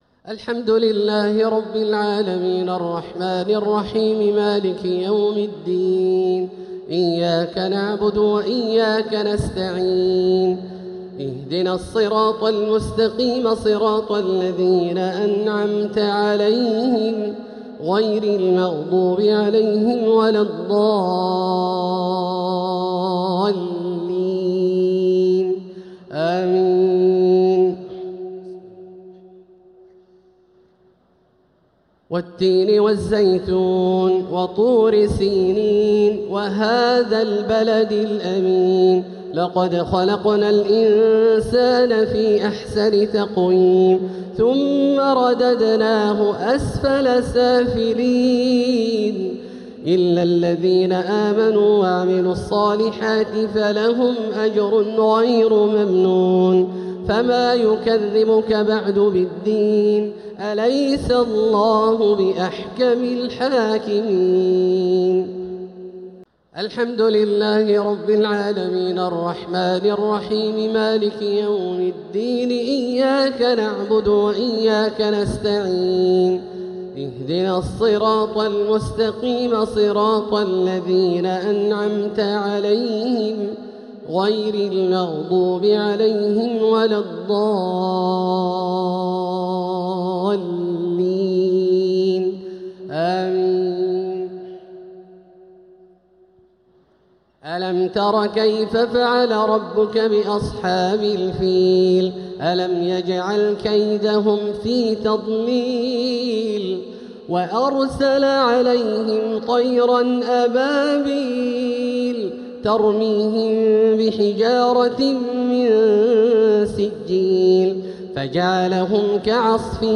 الشفع و الوتر ليلة 17 رمضان 1447هـ > تراويح 1447هـ > التراويح - تلاوات عبدالله الجهني